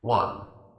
scpcb-godot/SFX/Room/Intro/PA/numbers/1.ogg at master